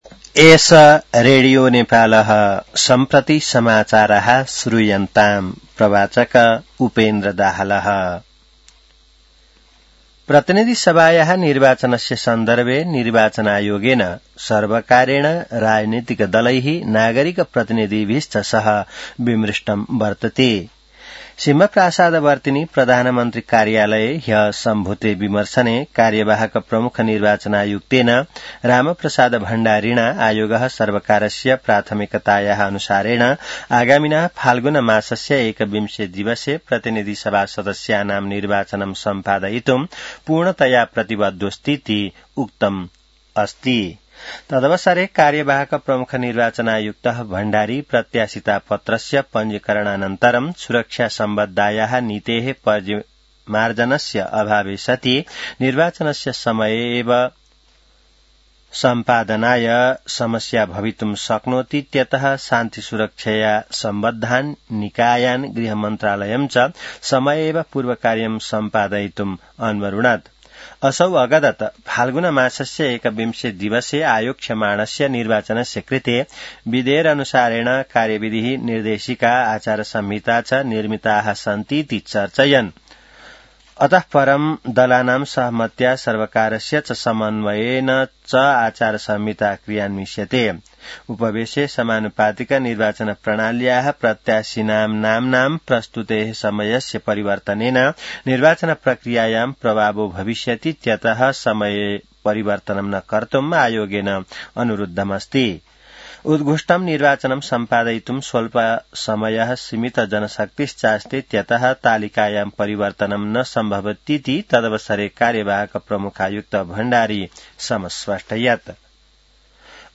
संस्कृत समाचार : ८ पुष , २०८२